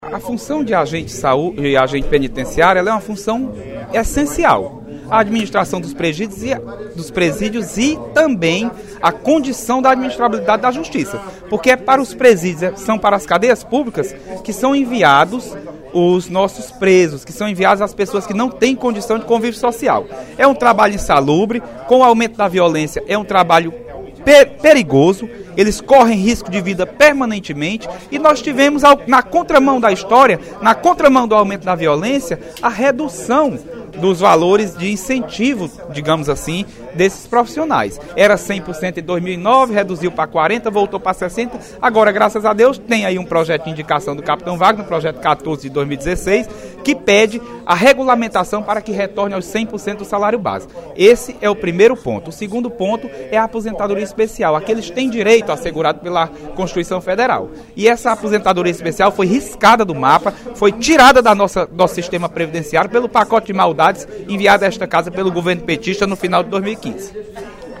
O deputado Leonardo Araújo (PMDB) cobrou, durante o primeiro expediente da sessão plenária desta sexta-feira (01/04), a sensibilidade do Governo do Estado no atendimento a demandas da categoria dos agentes penitenciários estaduais.